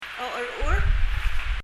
laugh.mp3